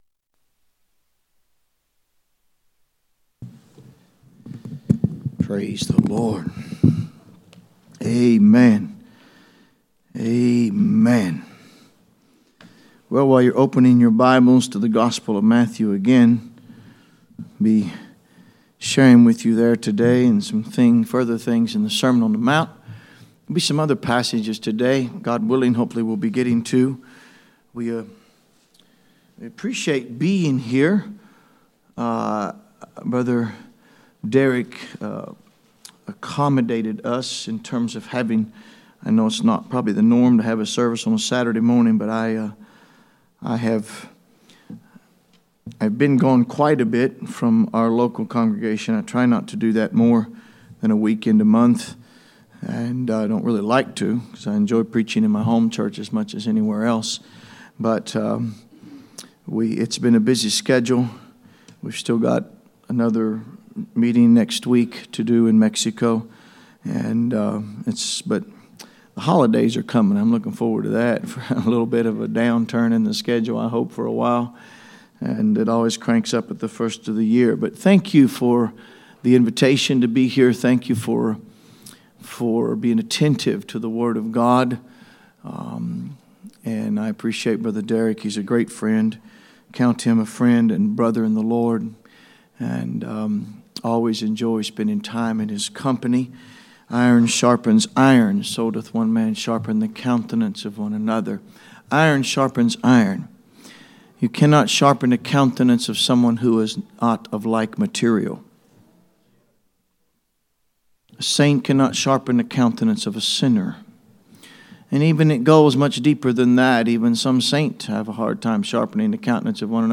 Passage: Matthew 5:20-48 Service Type: Midweek Meeting